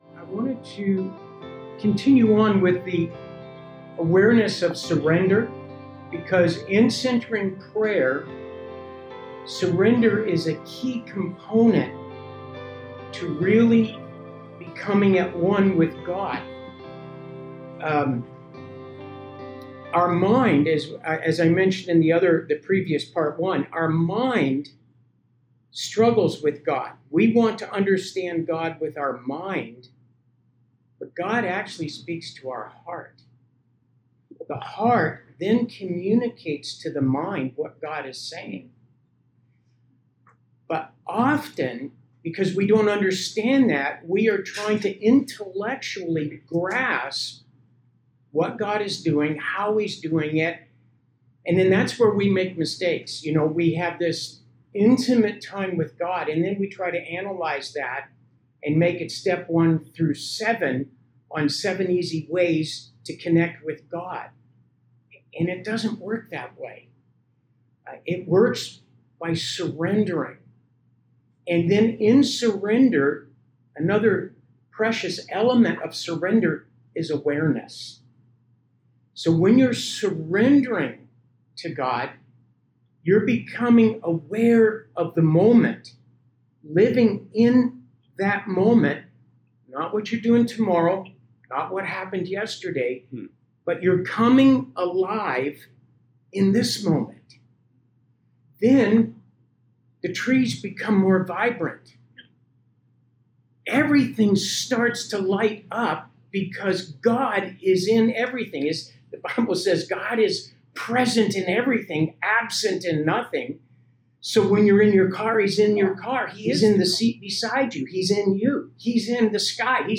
Watch the discussion session after a group session of centering prayer.